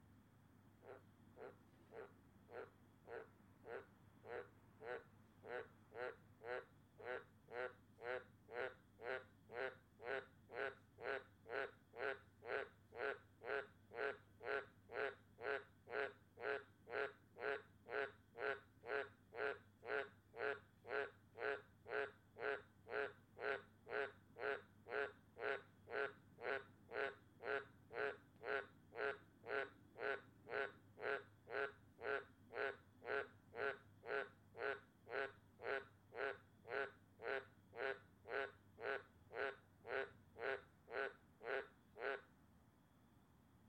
Logancircle2 环境噪音 " 鸟叫声丛林城市
描述：鸟鸣叫自然交通街道环境都市高速公路背景影片foley气氛大气声音噪声领域录音背景声音atmos
Tag: 高速公路 鸟儿鸣叫 音景 氛围 环境 背景 电影 交通 街道 背景音 ATMOS 性质 现场记录 噪声 大气 城市 弗利